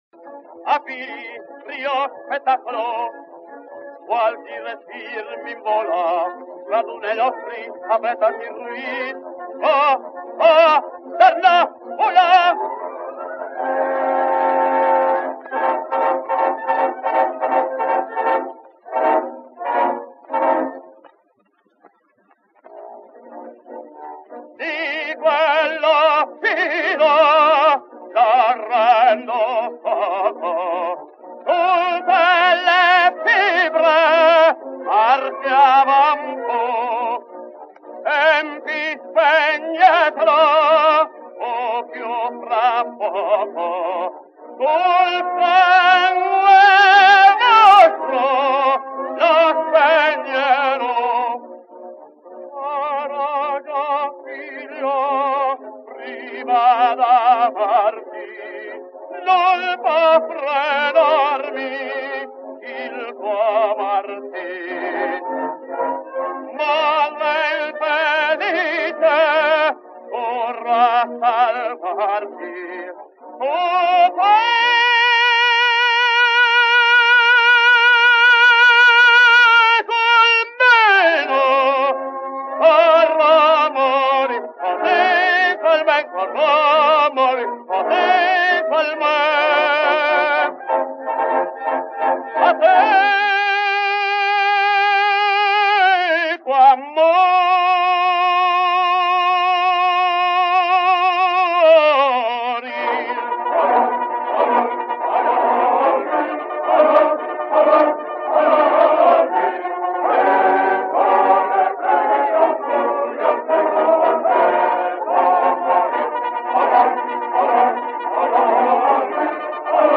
Il tenore Bernardo De Muro